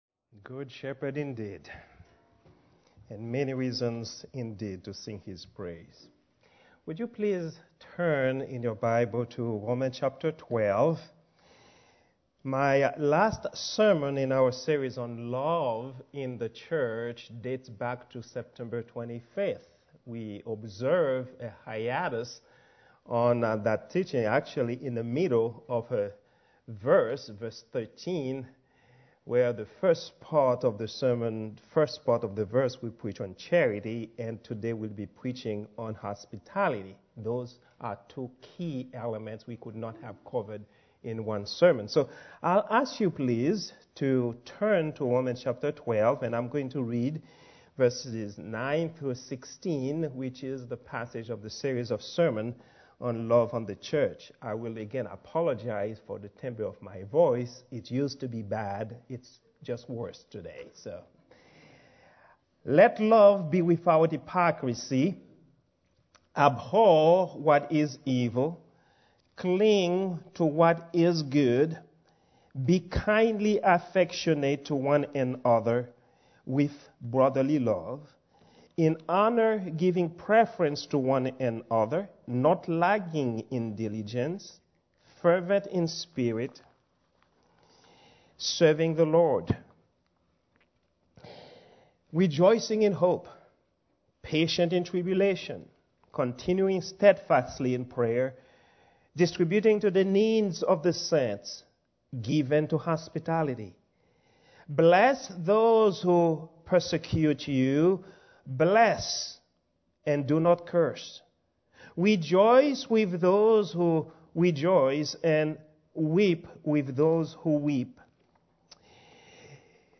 Love in the Church Sermon #5 - Beneficence of Love in the Church - Charity - Robinson Baptist